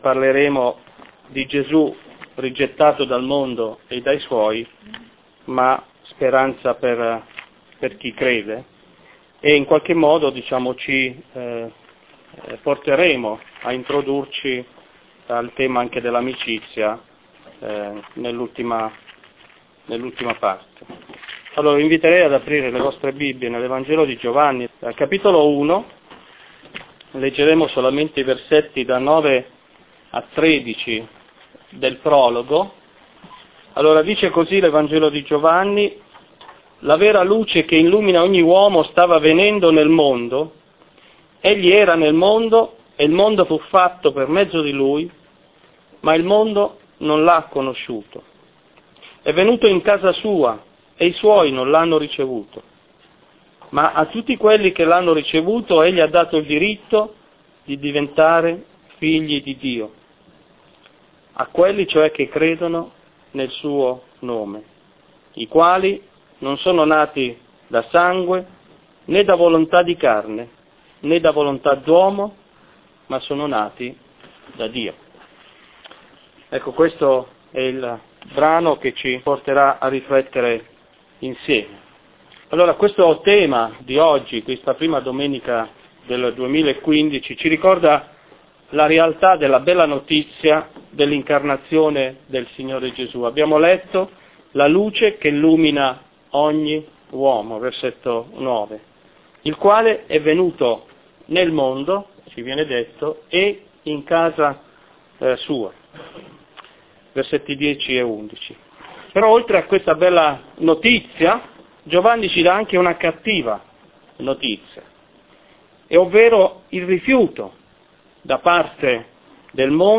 Predicatori